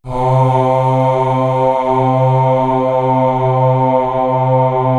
Index of /90_sSampleCDs/Best Service ProSamples vol.55 - Retro Sampler [AKAI] 1CD/Partition C/CHOIR AHH